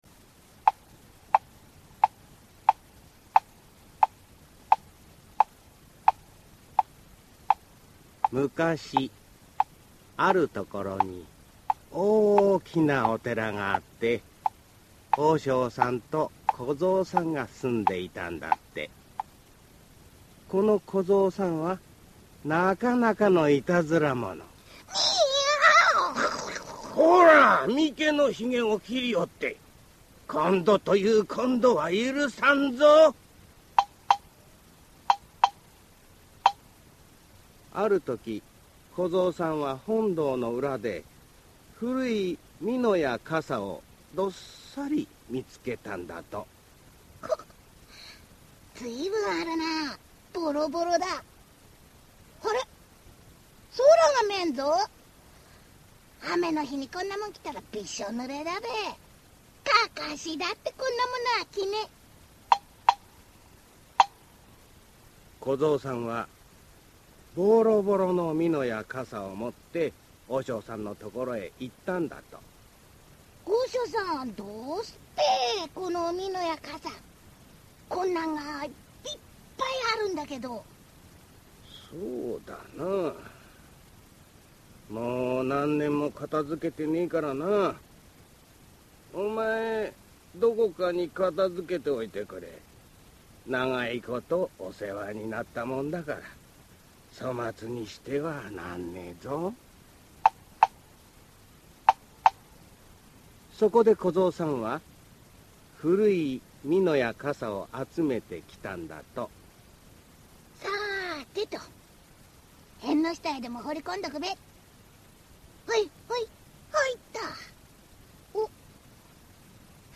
[オーディオブック] カサボコホイ ミノボコホイ